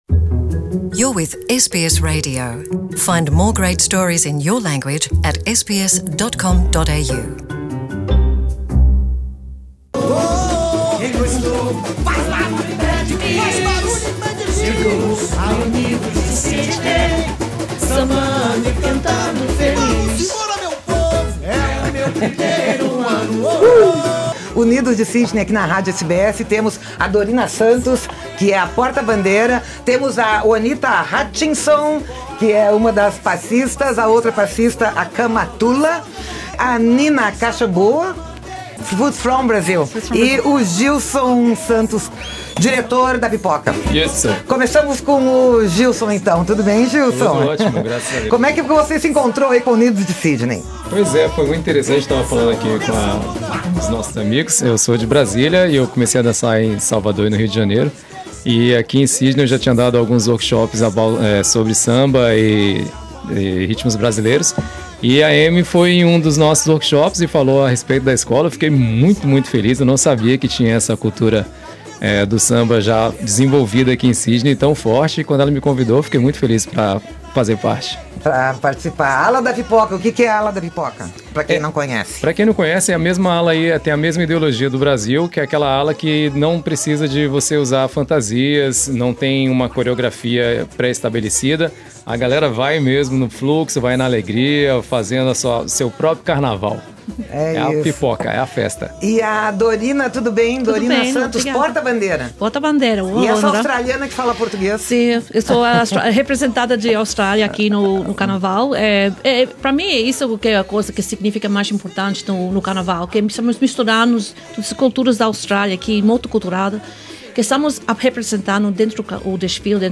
Unidos de Sydney nos estúdios da Rádio SBS Source: SBS Portuguese
Foi uma festa anos estúdios da Rádio SBS, com todos cantando o samba-enredo "Australiana".